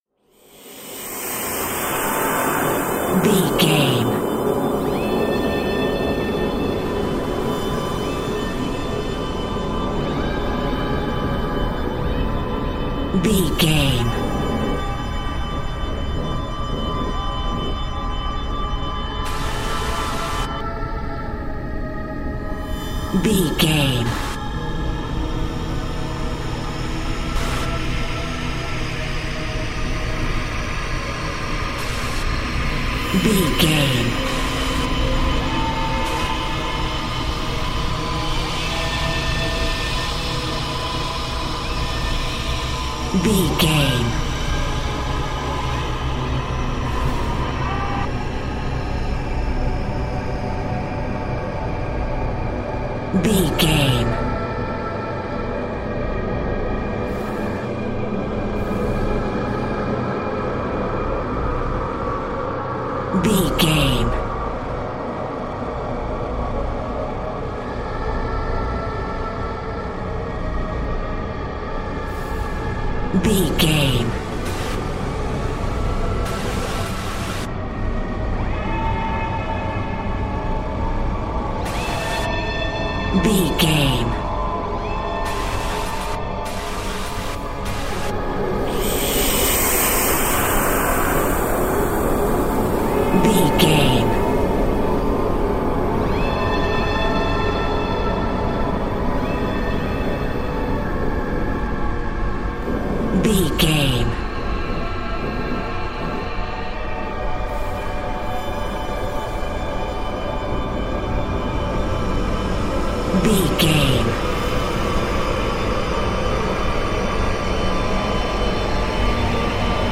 Scary Soundscape Industrial Cue.
Atonal
Slow
ominous
dark
eerie
synthesiser
Horror Pads
Horror Synths